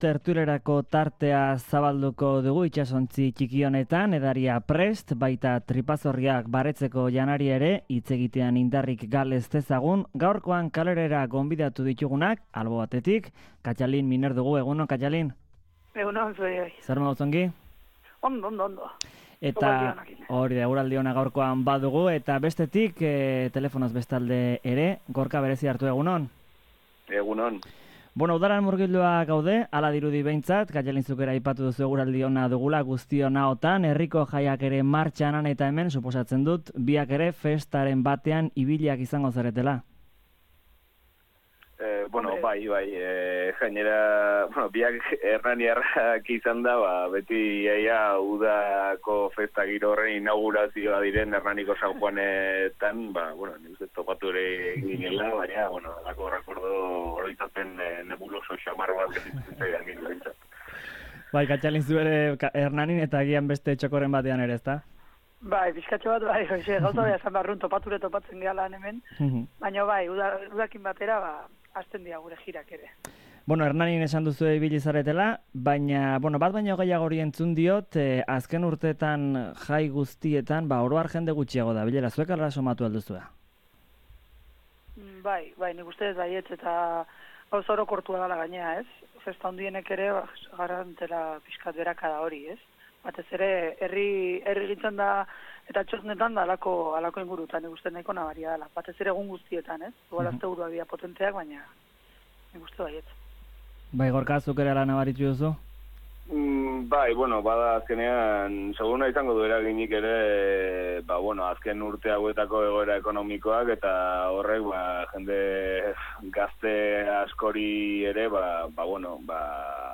Tertulia Karelean saioan